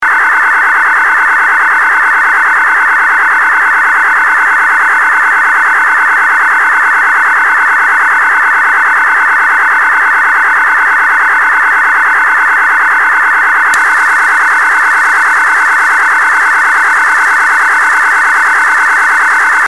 unknown signal1